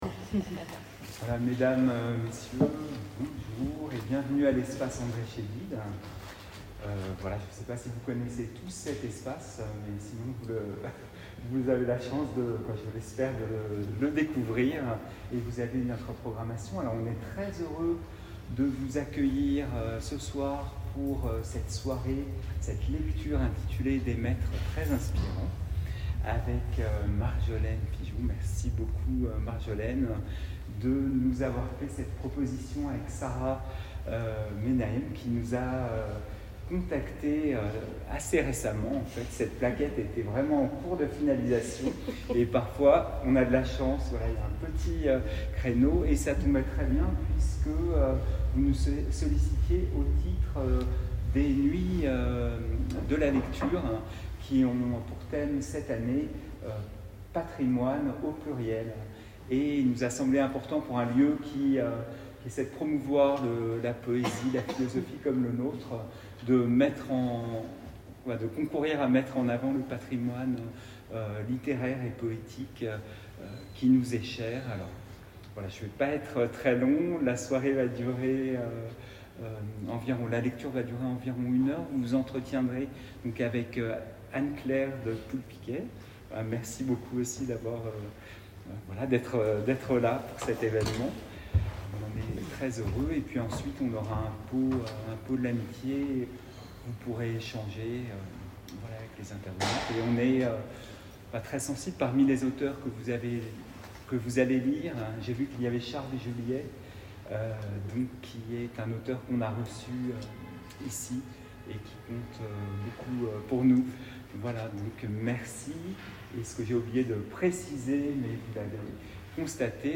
Entretien
dans le cadre des Nuits de la lecture 2025 conjointement à l’exposition Ouvrir les failles, Espace Andrée Chedid, Issy-les-Moulineaux